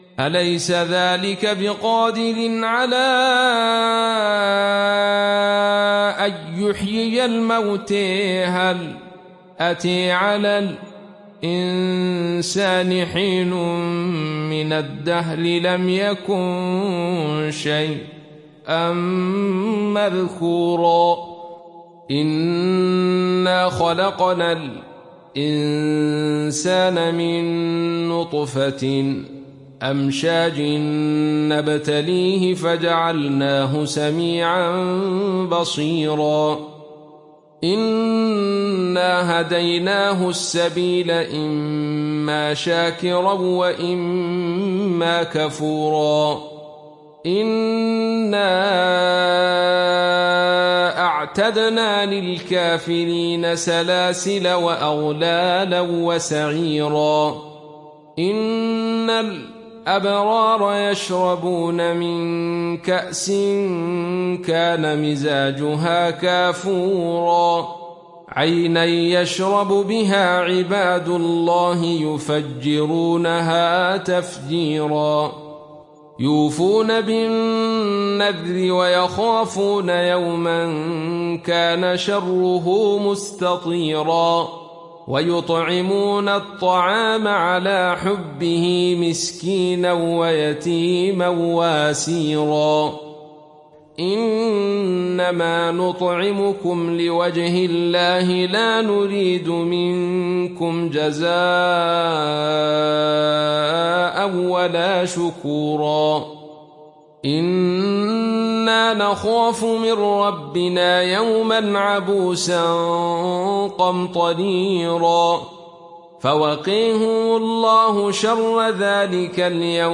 Surah Al Insan Download mp3 Abdul Rashid Sufi Riwayat Khalaf from Hamza, Download Quran and listen mp3 full direct links